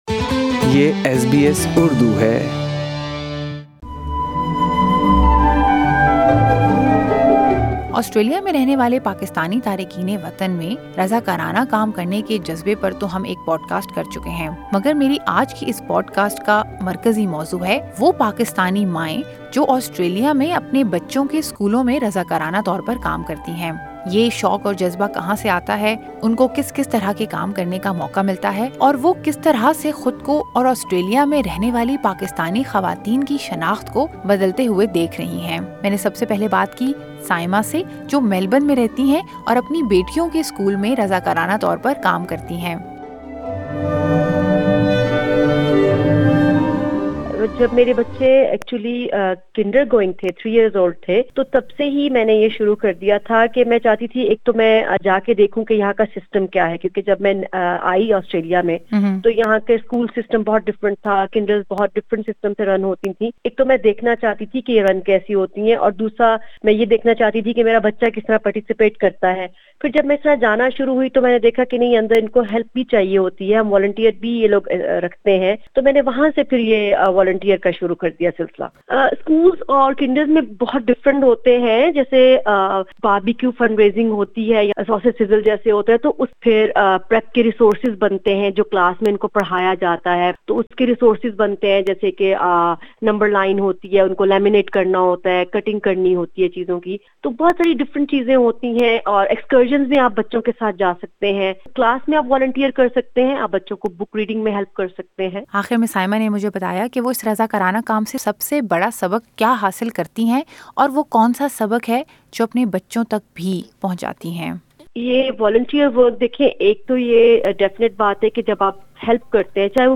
ایس بی ایس اردو نے رضاکارانہ کام کرنی والی چند تارکینِ وطن ماؤں سے گفتگو کی ہے۔